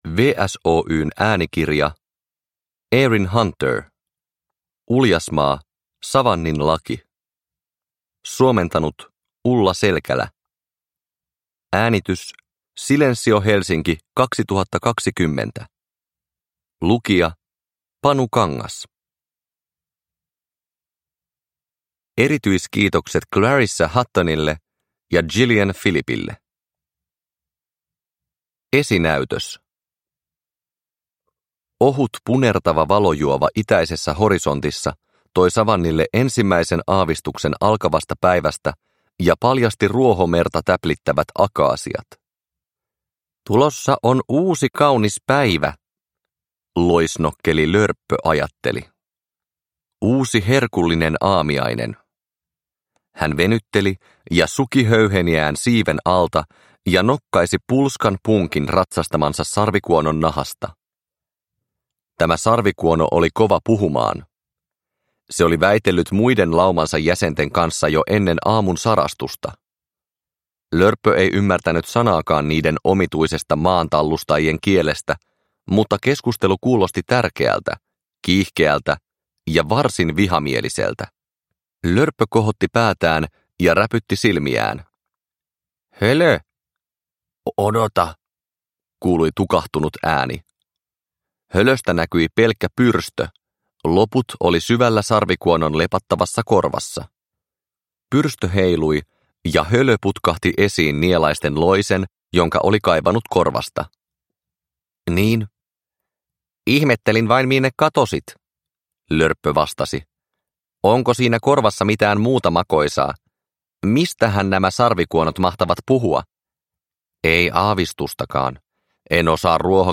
Uljasmaa: Savannin laki – Ljudbok – Laddas ner